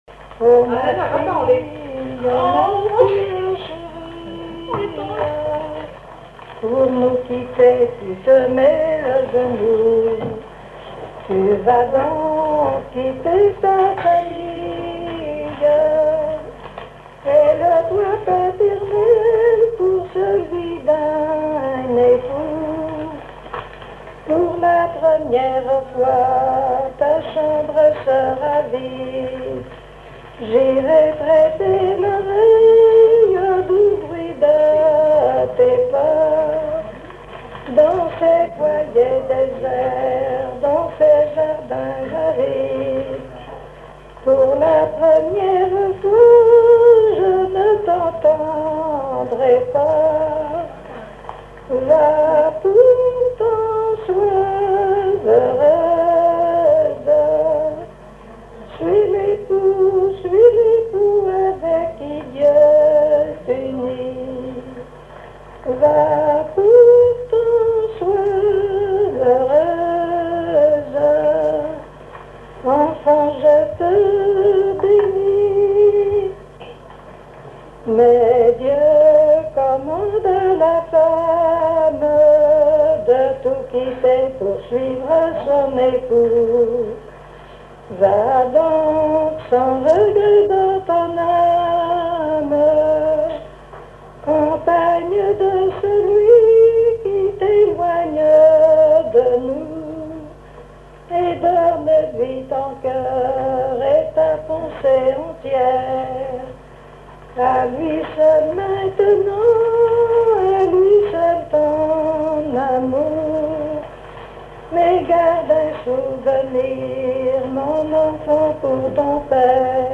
collecte en Vendée
chansons traditionnelles et commentaires
Pièce musicale inédite